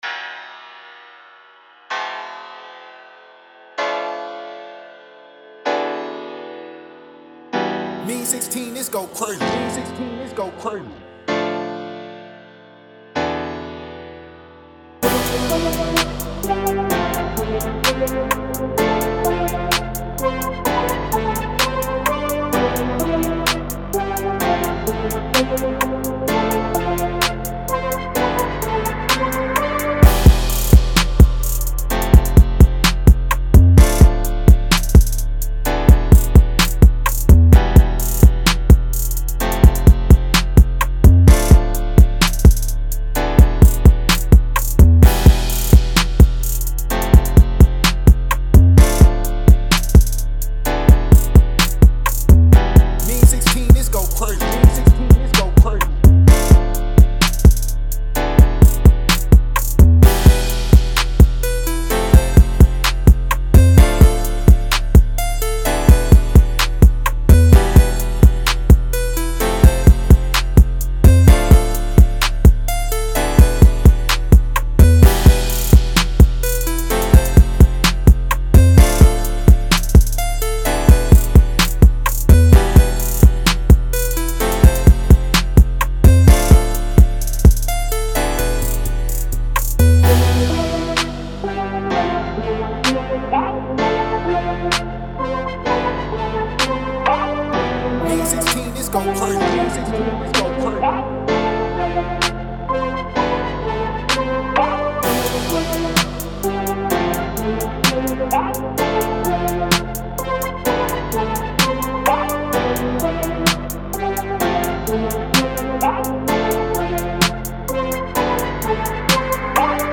A-Min 128-BPM